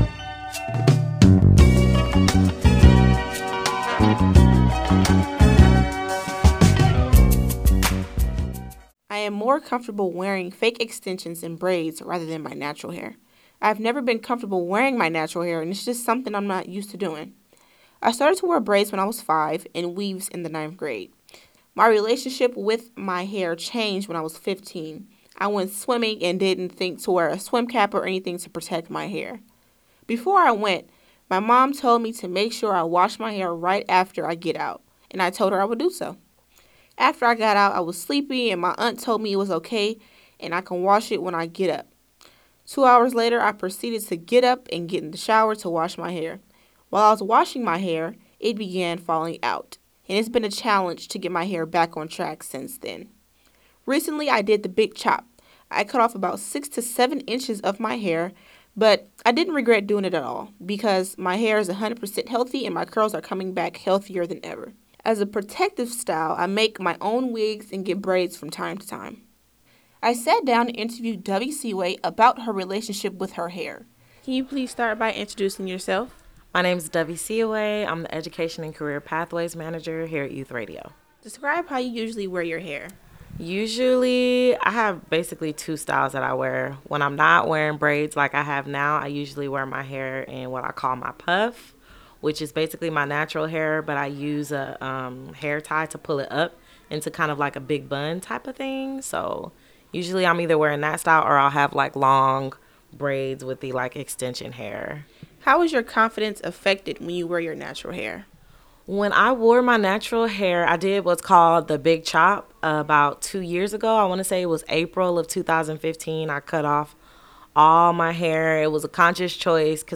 UNTOLD: A Conversation About Black Hair and Beauty